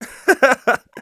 Voice file from Team Fortress 2 German version.
Scout_laughevil01_de.wav